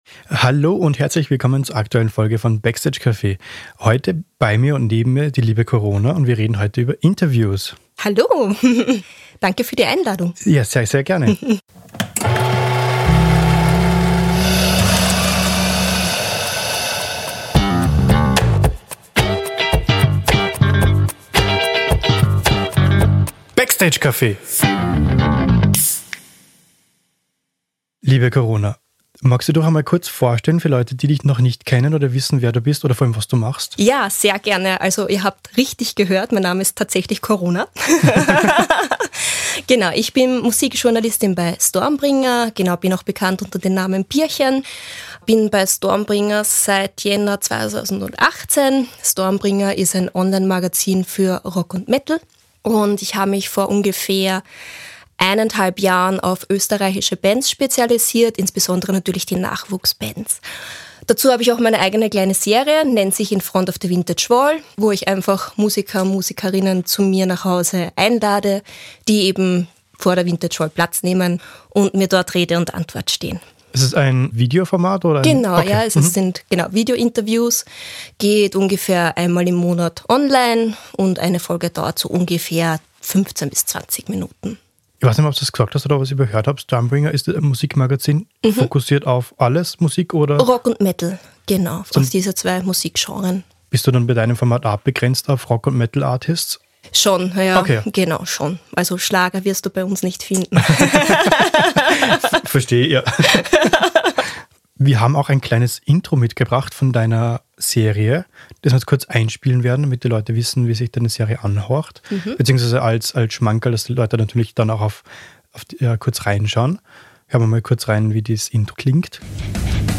Ein wertvoller Talk für alle, die Interviews nicht nur als Pflichttermin sehen, sondern als Chance!